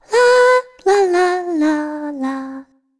Shea-Vox_Casting3.wav